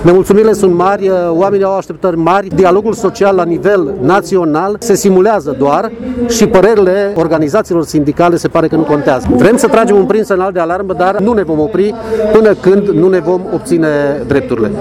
Zeci de sindicaliști au protestat astăzi în fața Prefecturii Mureș